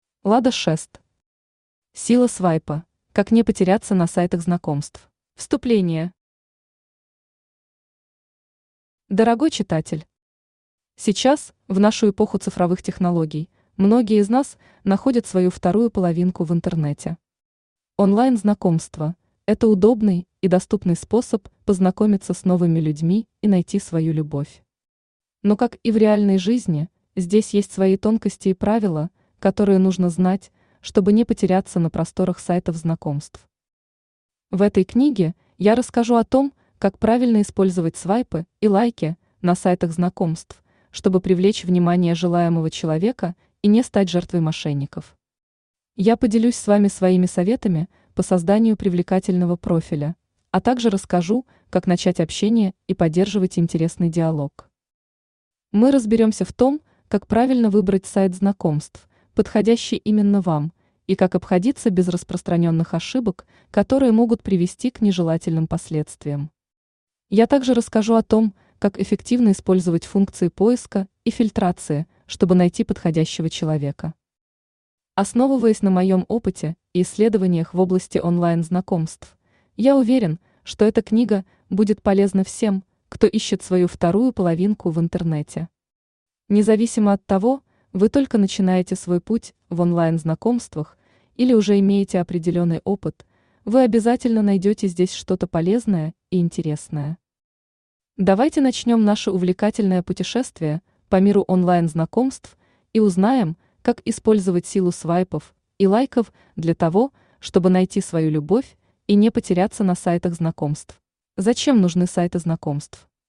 Aудиокнига Сила свайпа: как не потеряться на сайтах знакомств Автор Лада Шэст Читает аудиокнигу Авточтец ЛитРес.